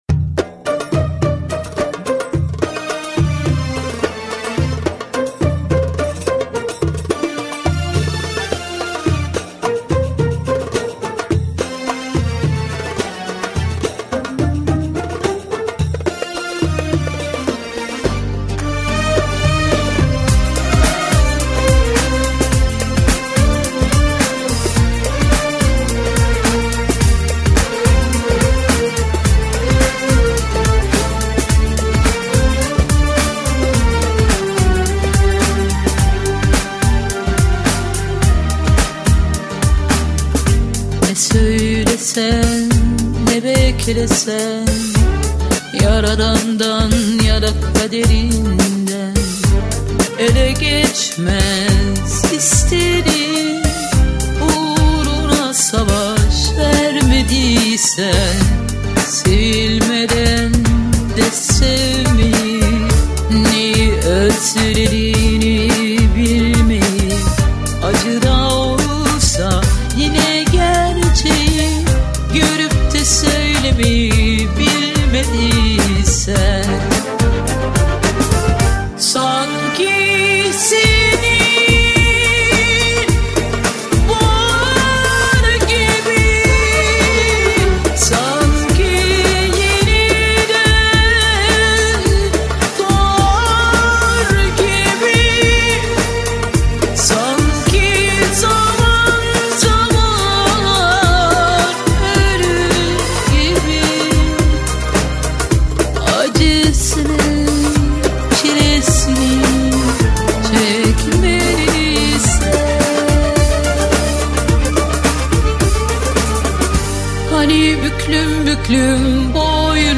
пронизана глубокими эмоциями и ностальгией